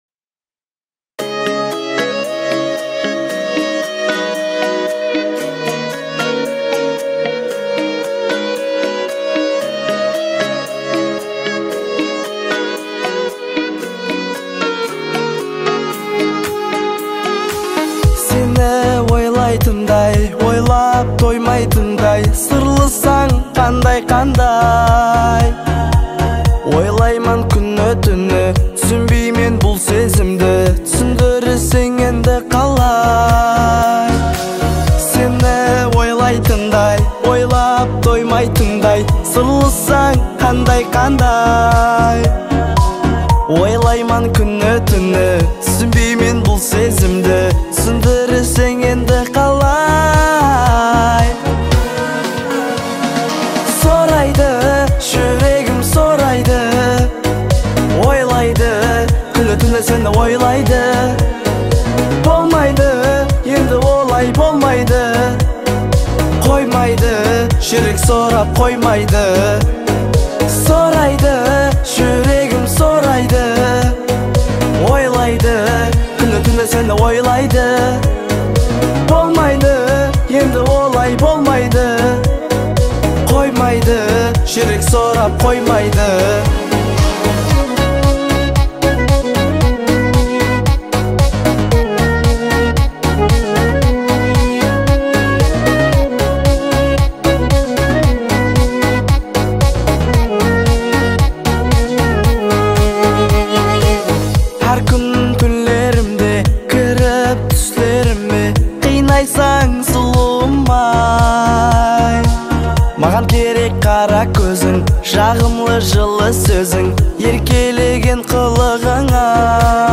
Категория: Узбекские